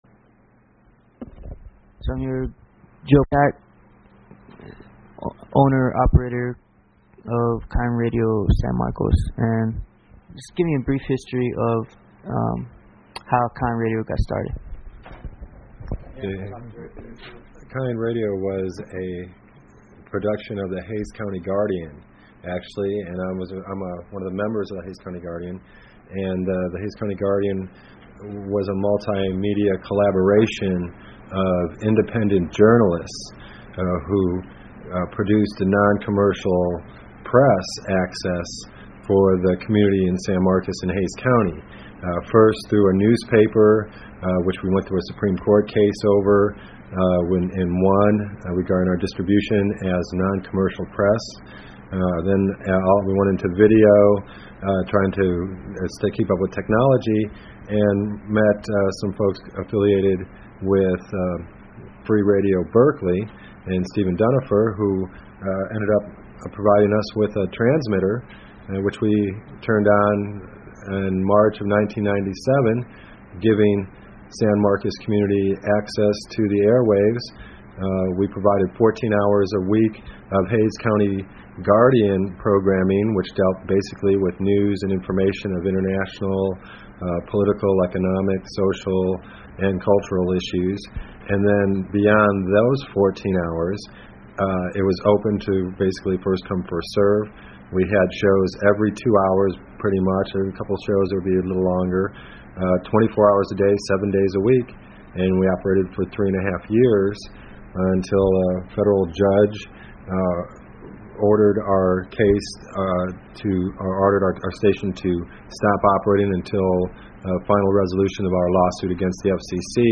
Two key figures from the mid-1990's microradio scene chat.